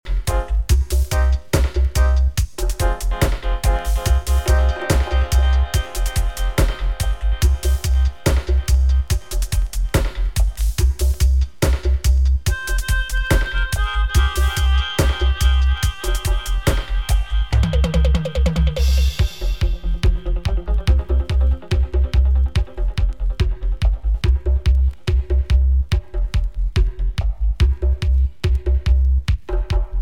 TOP >Vinyl >Grime/Dub-Step/HipHop/Juke